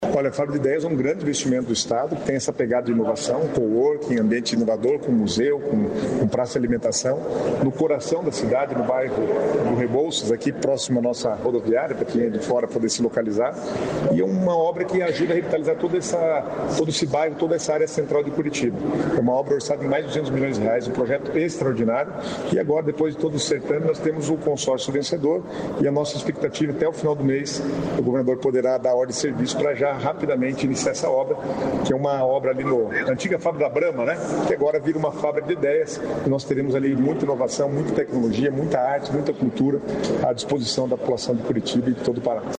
Sonora do secretário das Cidades, Guto Silva, sobre a fábrica de ideias